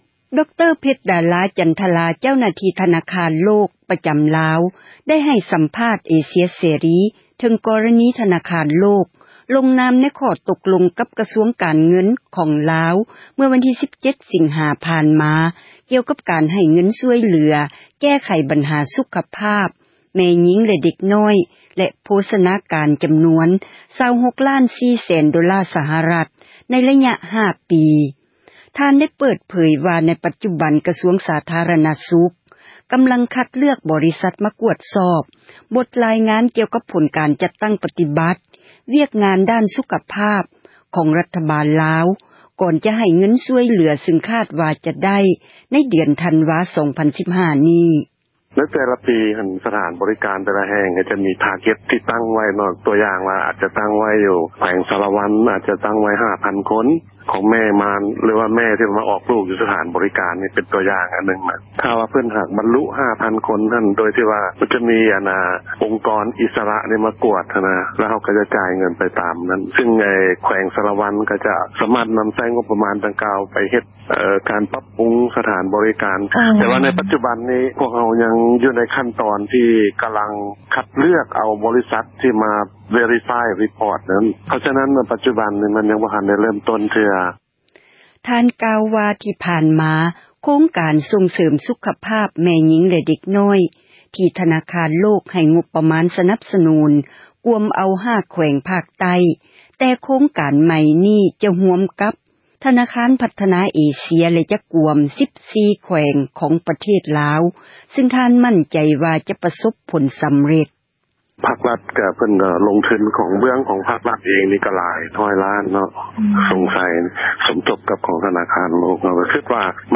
ໃຫ້ ສັມພາດ ກັບ ເອເຊັຽ ເສຣີ